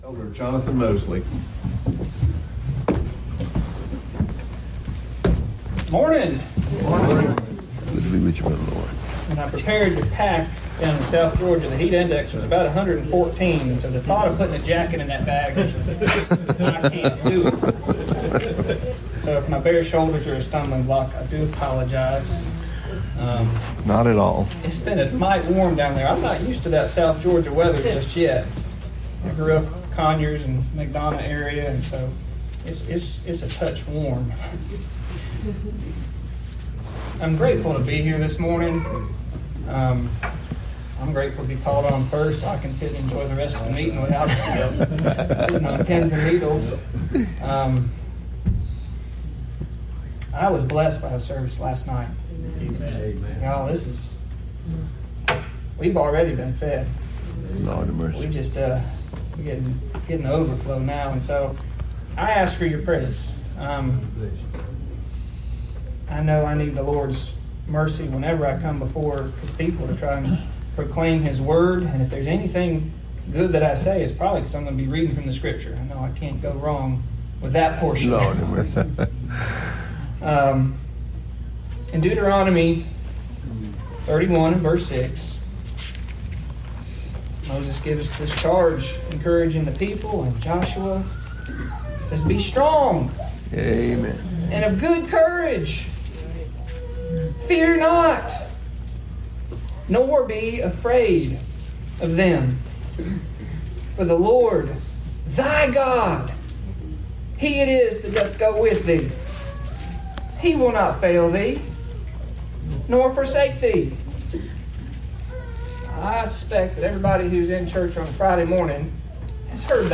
Little River August 2023 Meeting